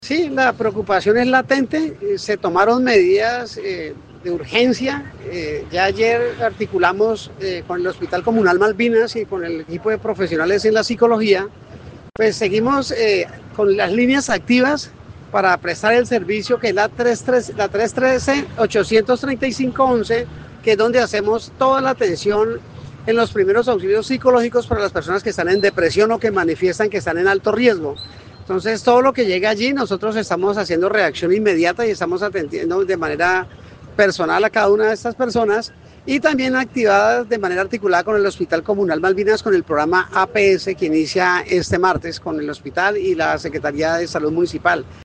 Miguel Ángel Galeano Mahecha, secretario para la gerencia del desarrollo humano de la alcaldía de Florencia, dijo que tras analizar el tema se han adoptado medidas de urgencia y junto al Hospital Malvinas, se trabajará en la asistencia de los denominados primeros auxilios psicológicos a aquellas personas con depresión o que manifiesten alto riesgo.